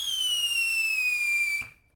whistle.ogg